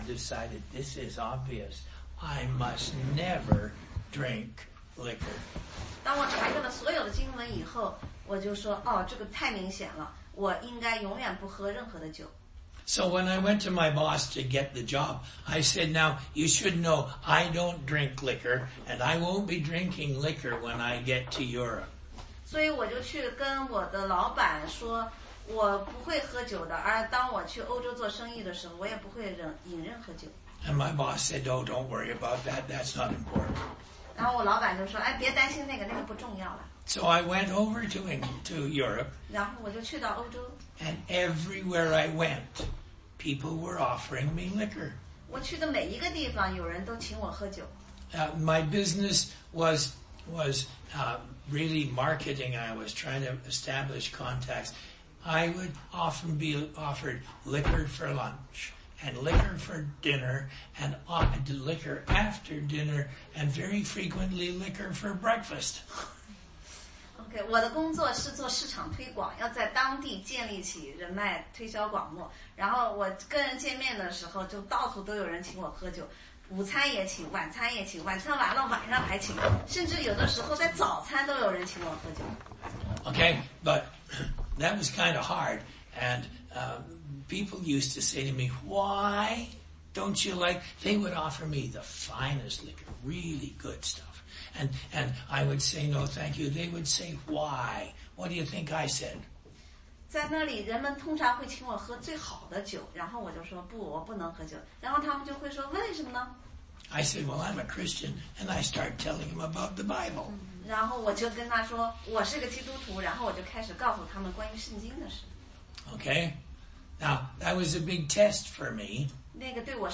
16街讲道录音 - 罗得名字的含义
答疑课程
抱歉， 录音不全， 只有后半部分。